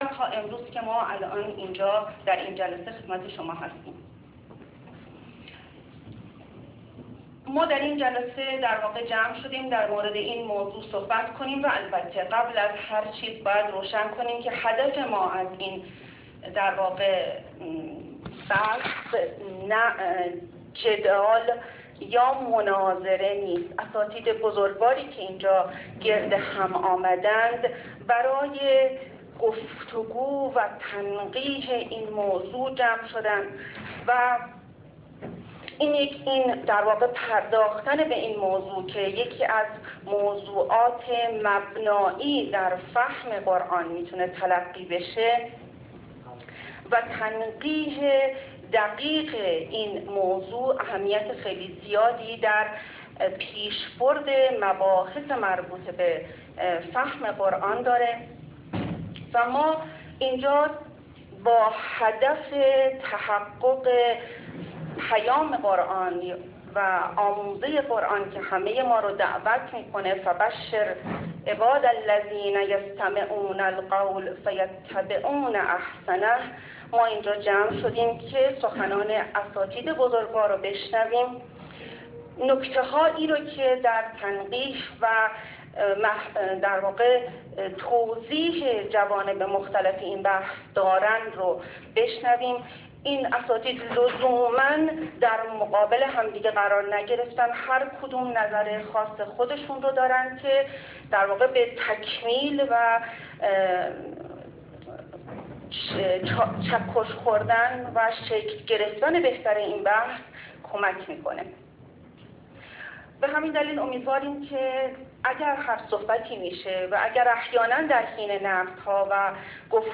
دانشکده الهیات دانشگاه تهران